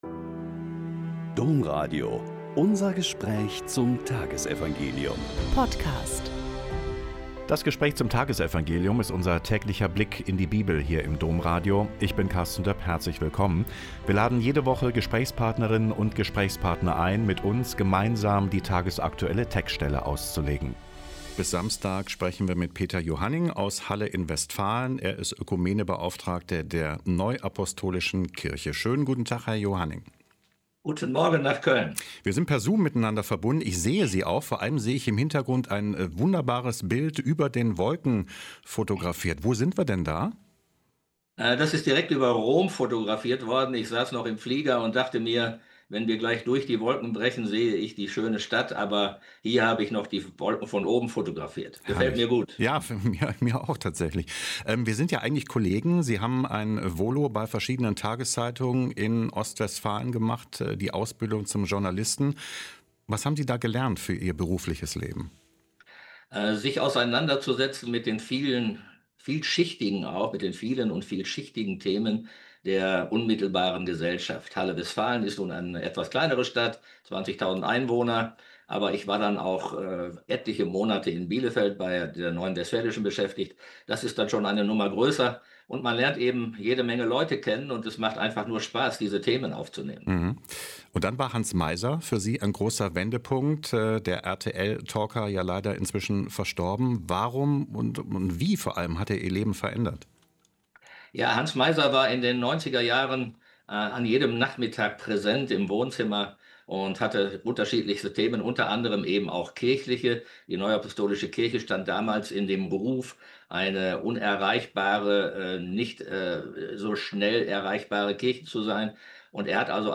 Mk 3,22-30 - Gespräch